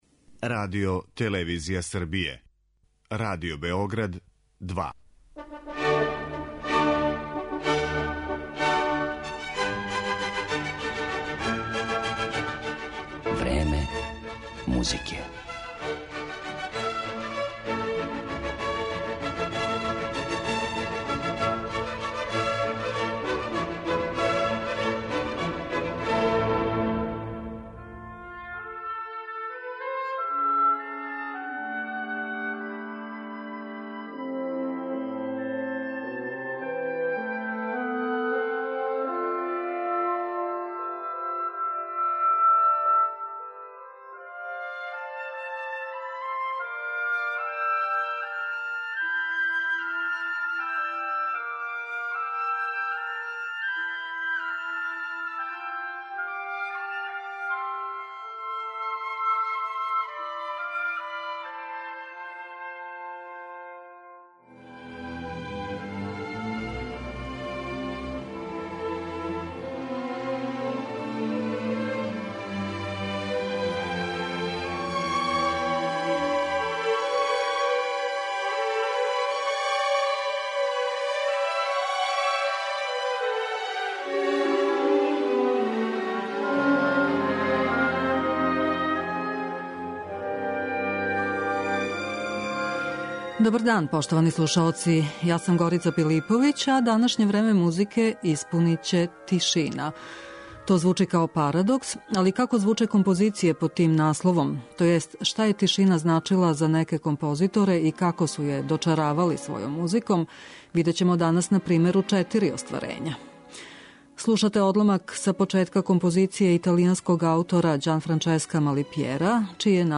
Мађарски неофолк
Распон њиховог приступа фолклору је веома широк - од стриктног неговања традиционалних начина извођења до крајње слободних интерпретација у којима се преплићу веома различити музички жанрови.